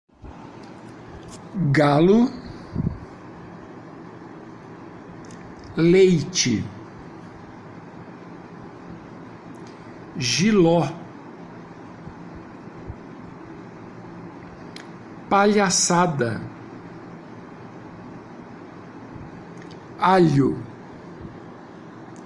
Faça download dos arquivos de áudio e ouça a pronúncia das palavras a seguir para transcrevê-las foneticamente.
GRUPO 3 - Laterais - Arquivo de áudio -->